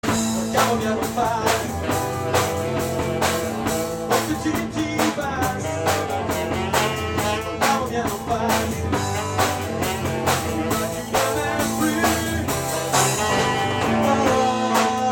Enregistrement mini-disc (29.12.2001)